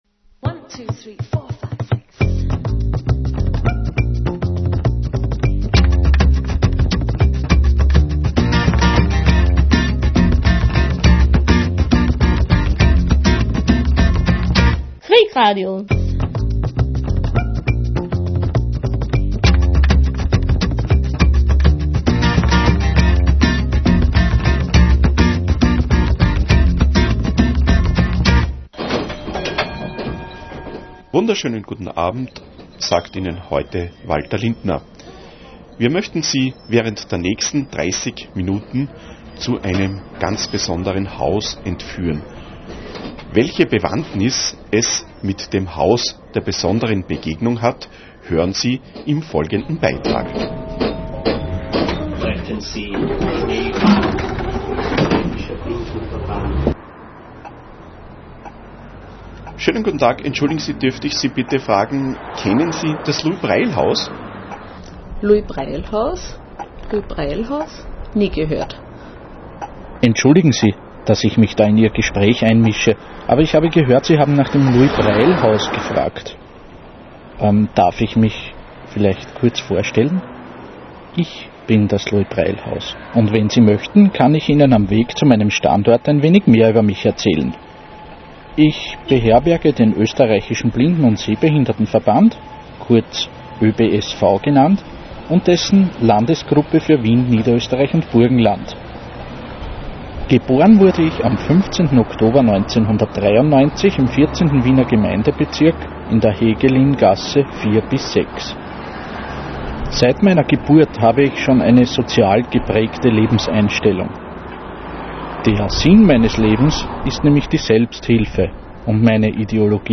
In einem Feature stellt sich das Louis-Braille-Haus, seine Gäste und seine Mitarbeiter vor.